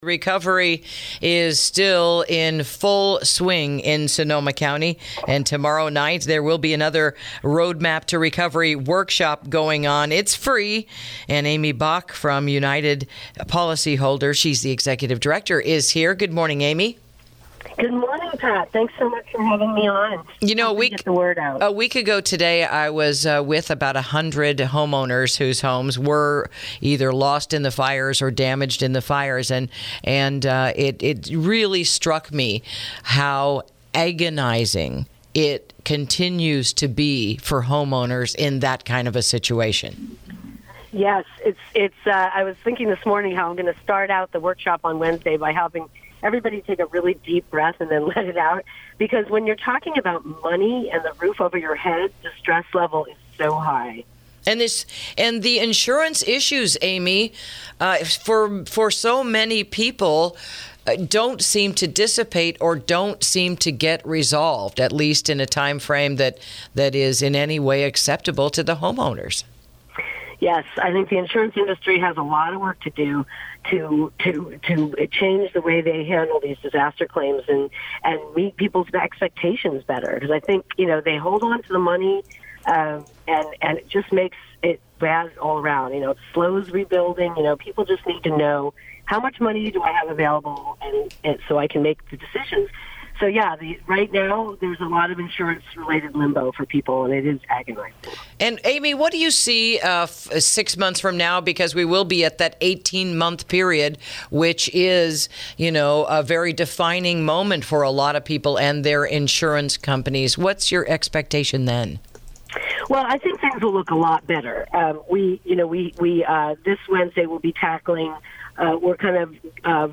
INTERVIEW: Free Roadmap to Recovery Workshop Wednesday Night in Santa Rosa